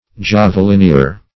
Javelinier \Jave`lin*ier"\, n. A soldier armed with a javelin.